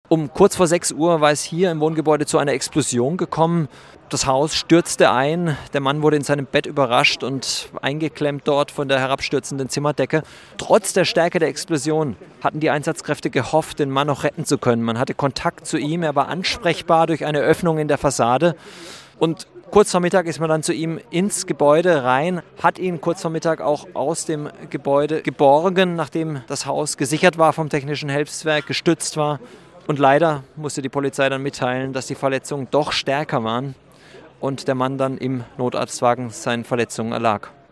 3. Nachrichten